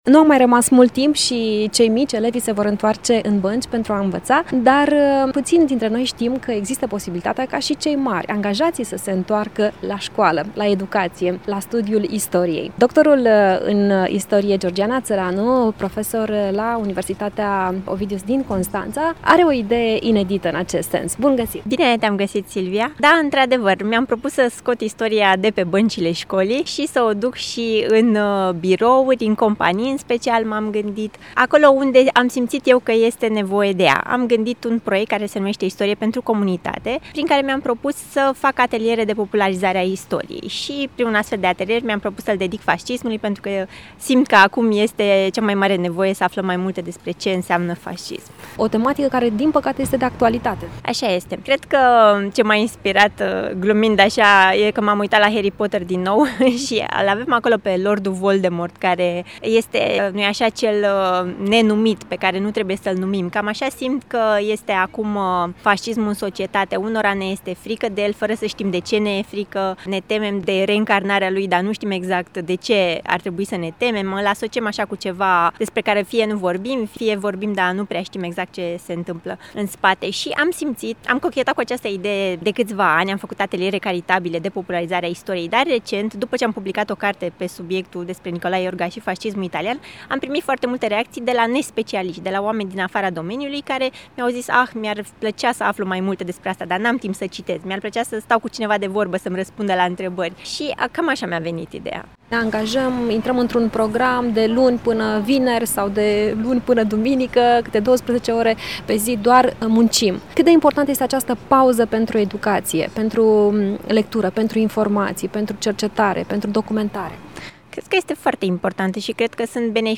De ce este important să înțelegem istoria, să ne uităm la greșelile trecutului și să analizăm cu grijă detaliile prezentului, aflăm din interviul